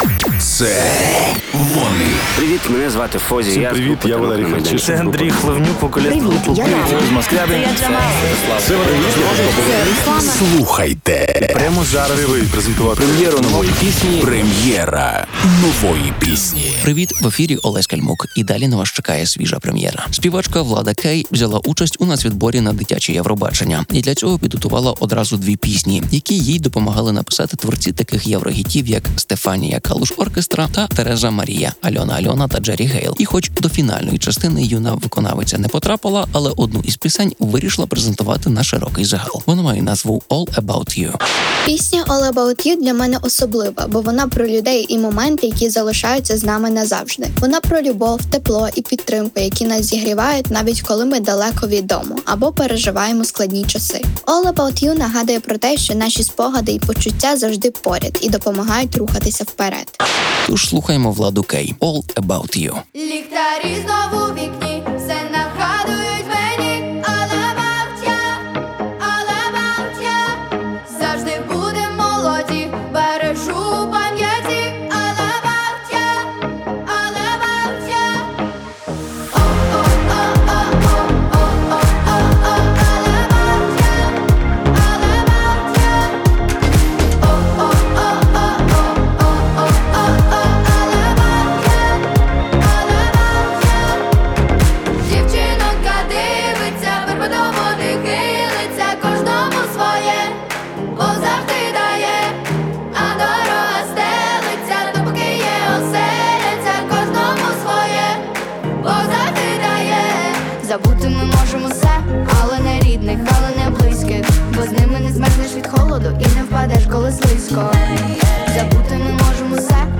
молода українська співачка